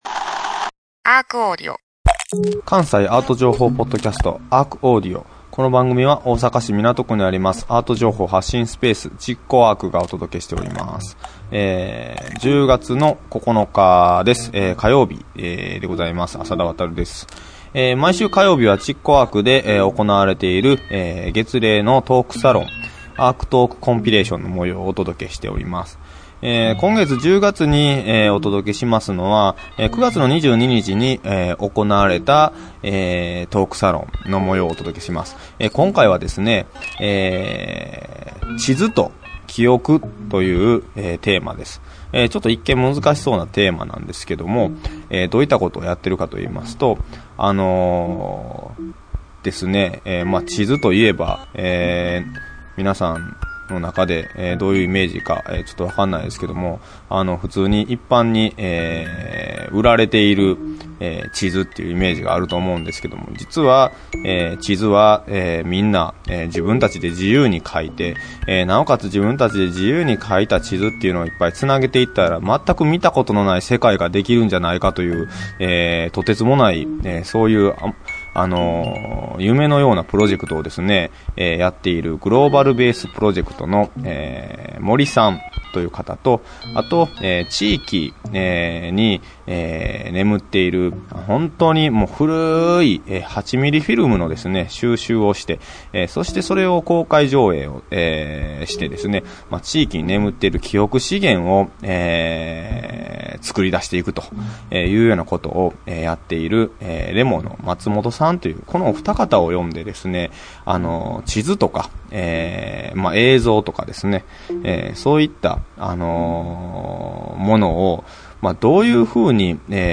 毎週火曜日は築港ARCにて毎月開催されるARCトークコンピレーションの模様を全4回に分けてお届けします。